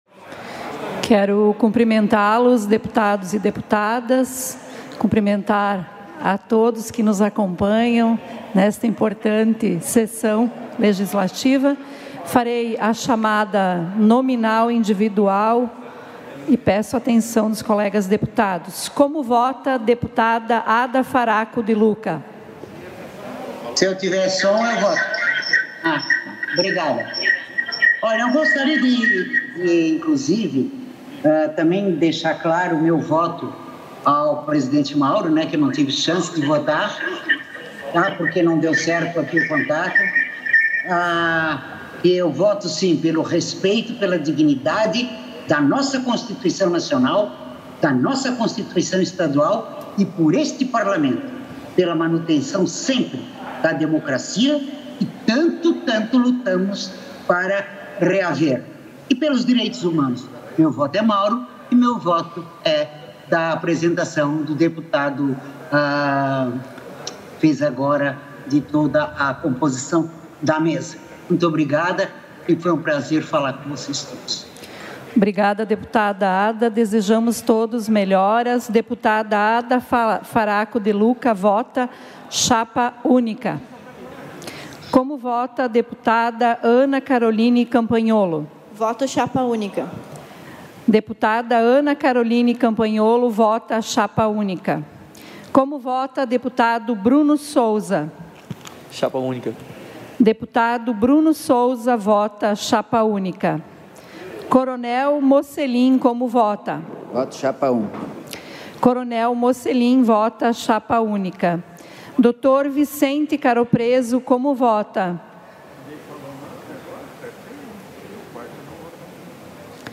Confira os pronunciamentos dos deputados durante a sessão preparatória desta segunda-feira (1º) para a eleição do presidente e da Mesa Diretora da Assembleia Legislativa para o biênio 2021-2023.